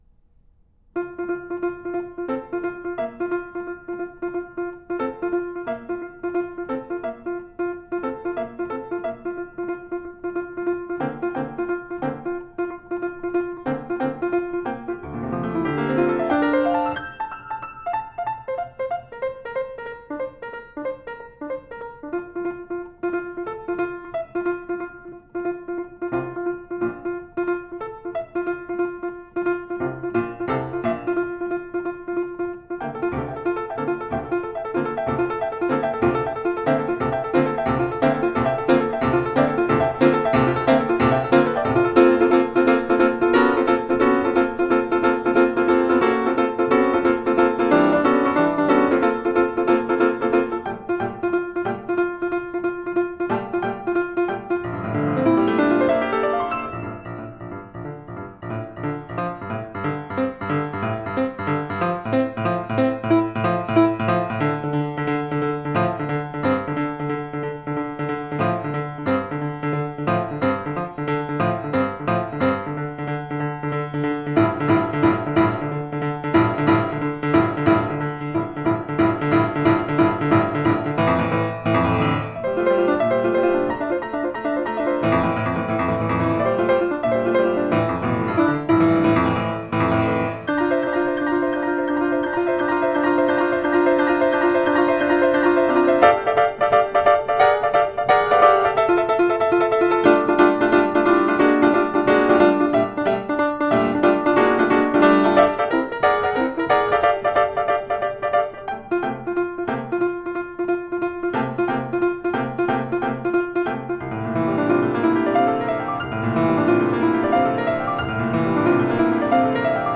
Recorded live in concert, March 22, 2015